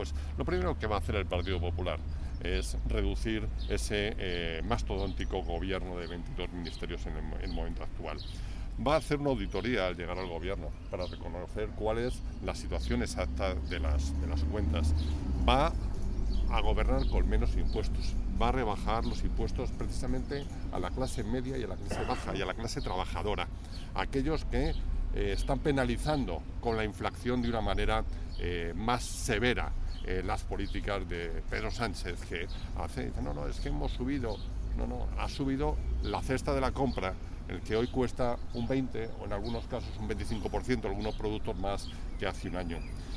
El también director provincial de campaña de estas elecciones generales se refirió durante la rueda de prensa a algunas de las primeras medidas de gobierno anunciadas ayer mismo por Feijóo con la idea de “un cambio tranquilo que fortalezca el estado de derecho y genere prosperidad”.
Corte-sonido-PP-Antonio-Roman-explica-primeras-medidas-que-llevara-a-cabo-el-gobierno-del-PP.mp3